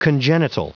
Prononciation du mot congenital en anglais (fichier audio)
Prononciation du mot : congenital